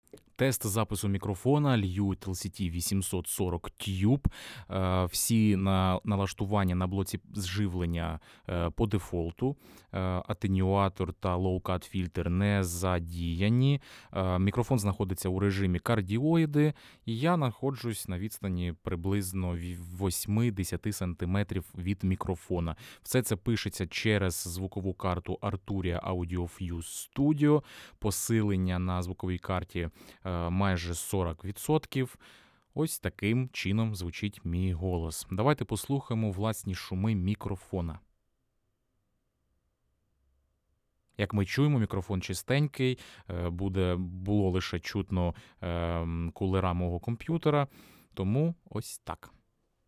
ewitt-lct-840-priklad-golosu.mp3